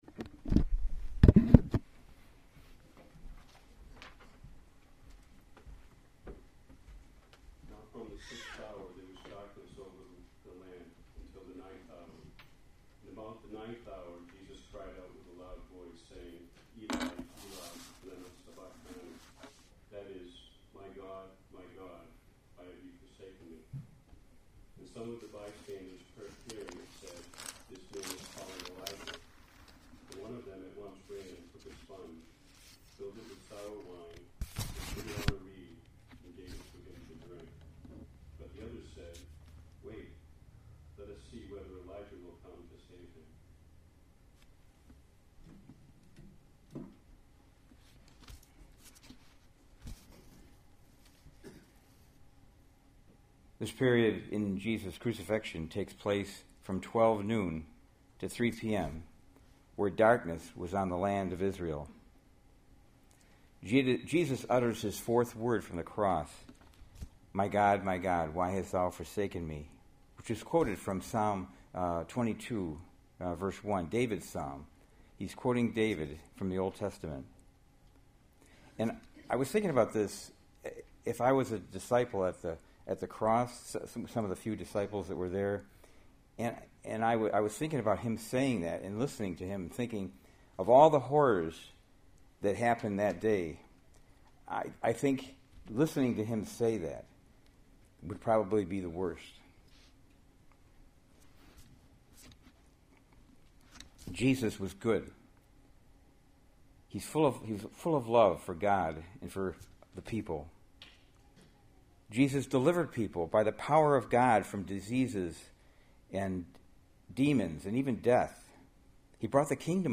Good Friday Tenebrae Service, Part 2 • Church of the Redeemer Manchester New Hampshire